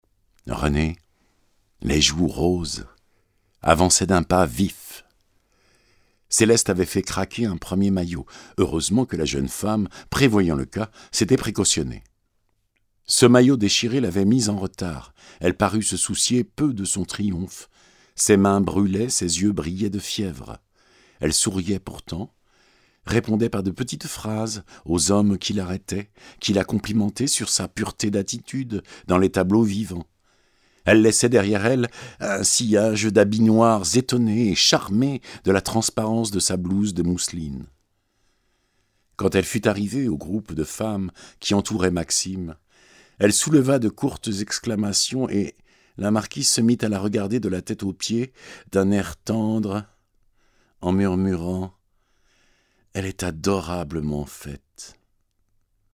audi livre